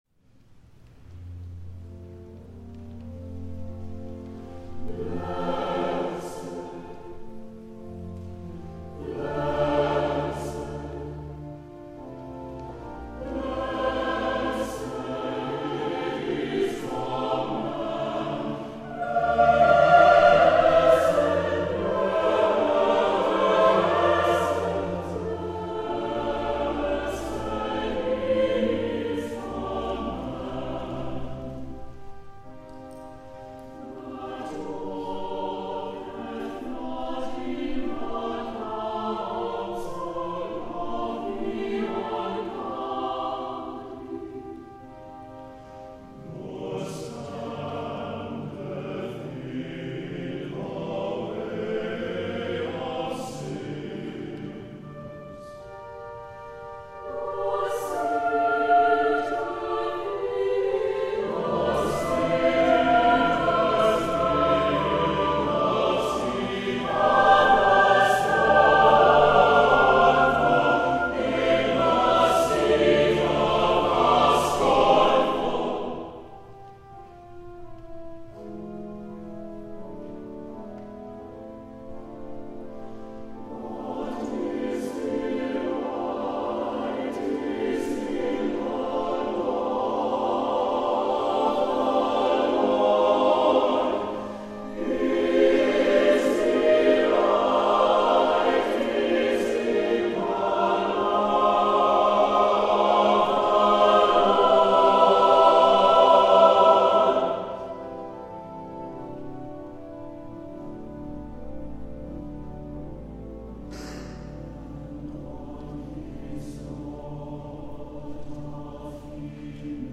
A large-scale setting of Psalm 1; SSATBB and organ